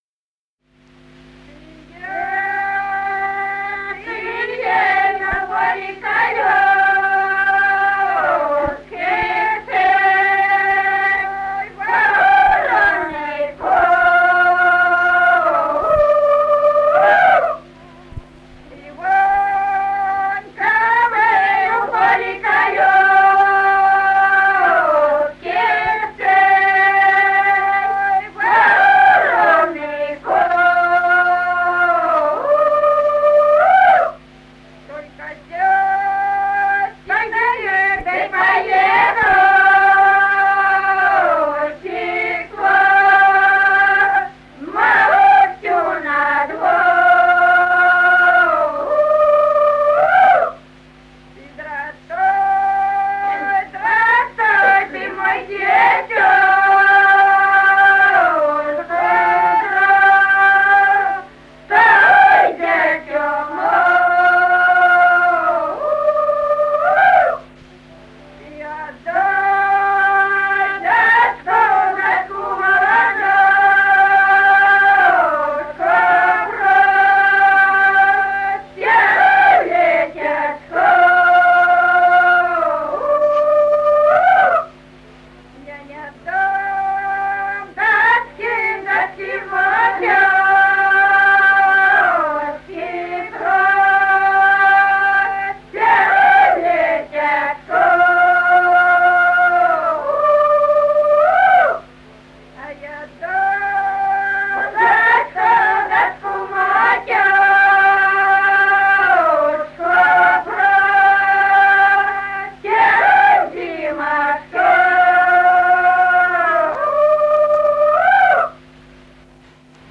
Народные песни Стародубского района «Чие, чие на поле колёски», жнивная.